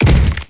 gibheavy1.wav